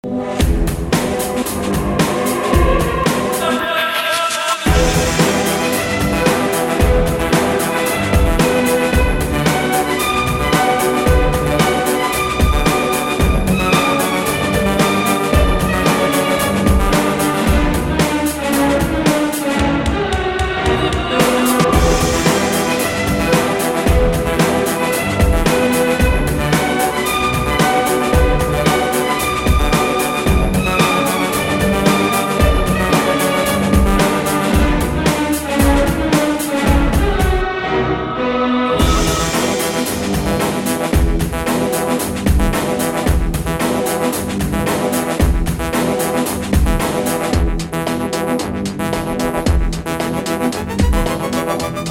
remix
классика
Знаменитая классическая композиция